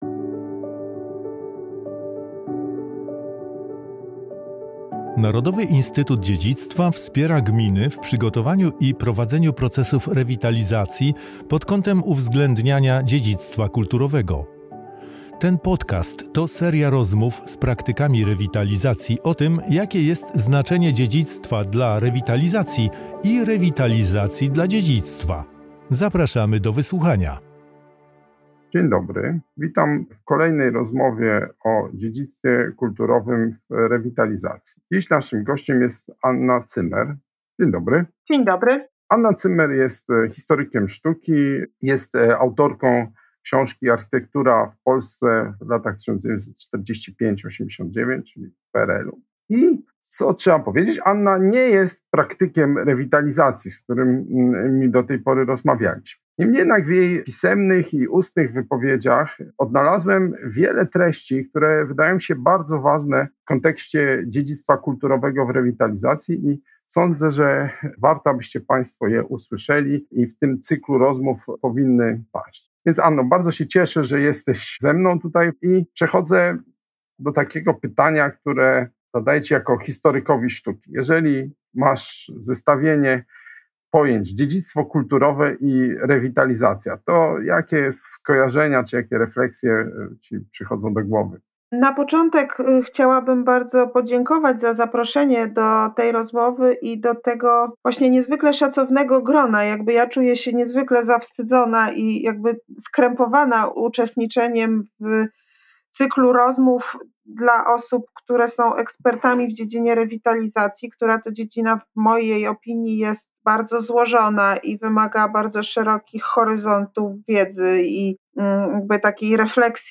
W cyklu rozmów z praktykami rewitalizacji kolejna rozmowa jest odstępstwem od reguły.